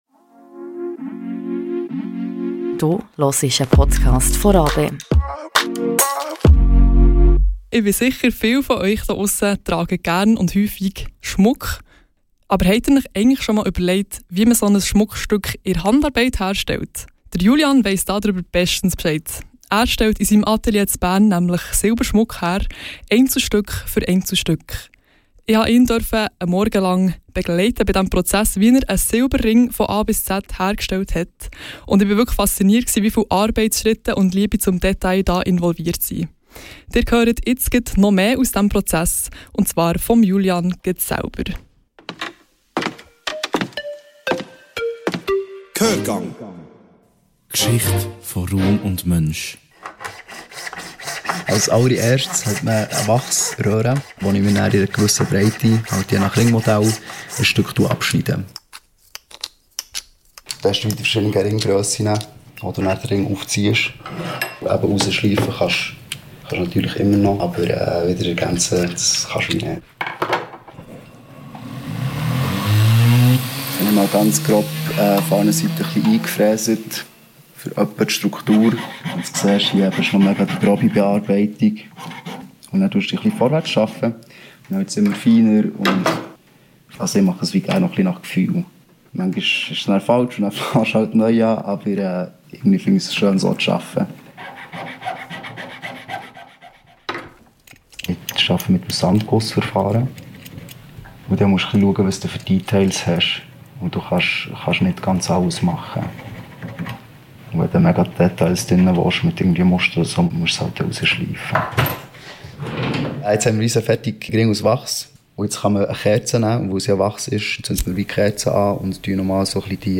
Ein Gehörgang durch das Schmuck-Atelier